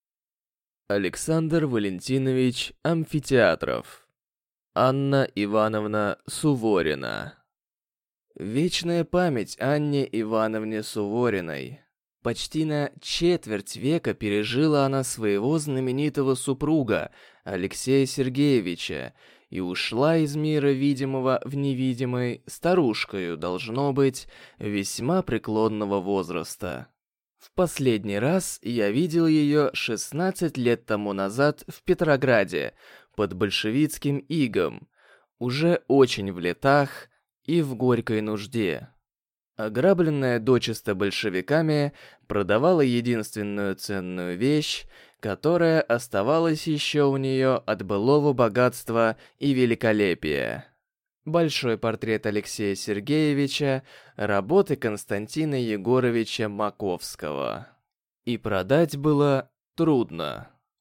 Аудиокнига А. И. Суворина | Библиотека аудиокниг